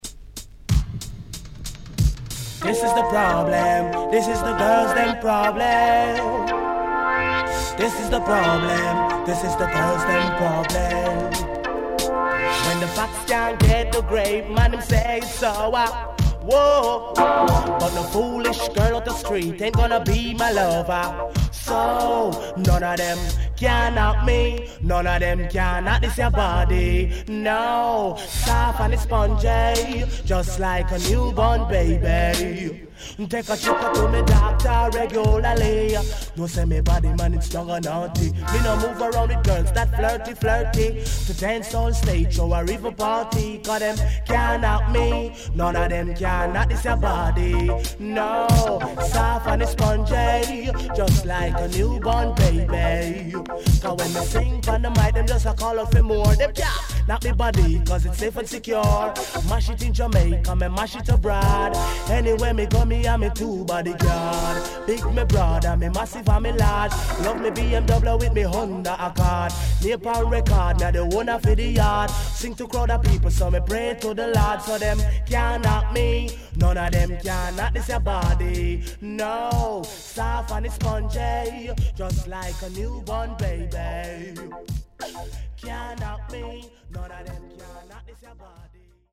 HOME > Back Order [DANCEHALL DISCO45]  >  KILLER
SIDE A:少しチリノイズ、プチノイズ入ります。